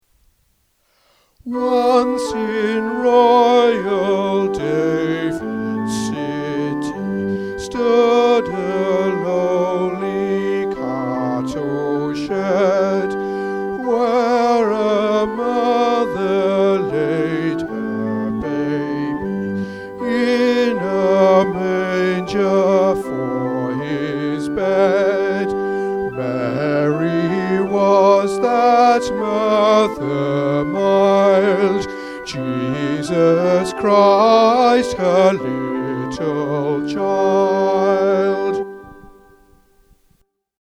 Once In Royal David’s City – Bass | Ipswich Hospital Community Choir